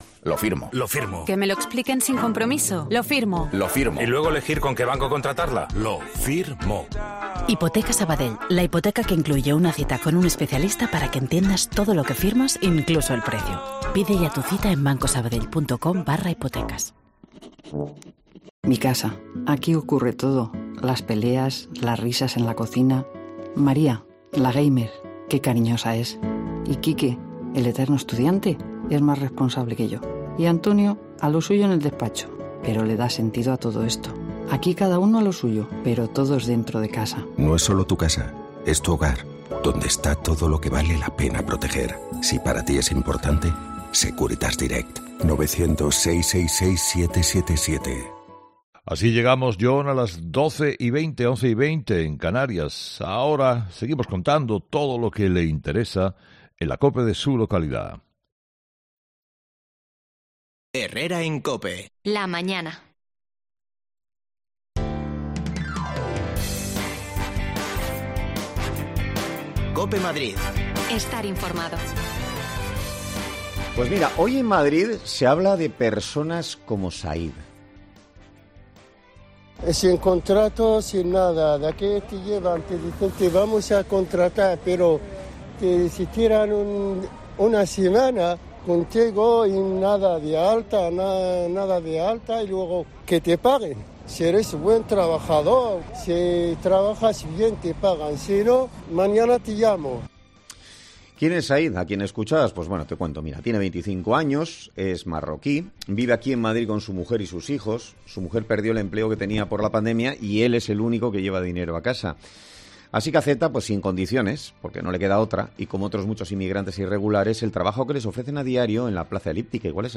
Importante operación policial en Plaza Eliptica contra la explotación laboral. Nos acercamos hasta alli para hablar con algunos de los inmigrantes que la sufren a diario